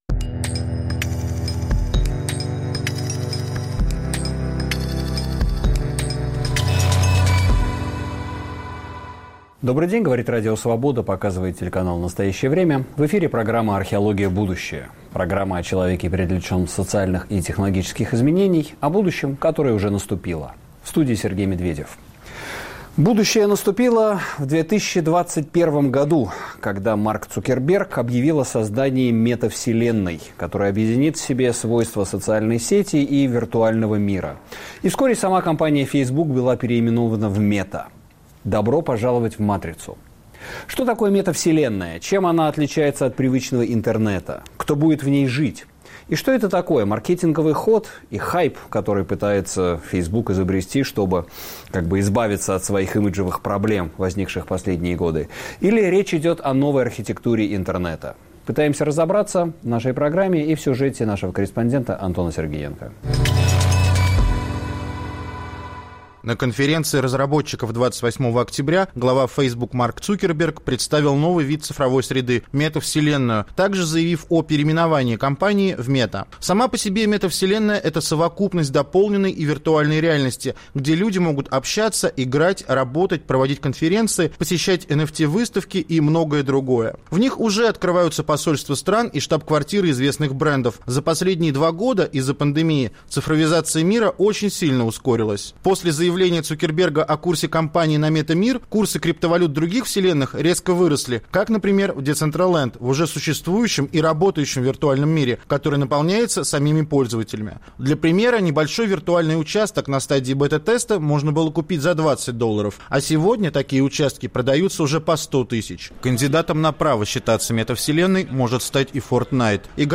Кто будет жить в метавселенной? В студии